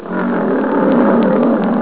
lion2.wav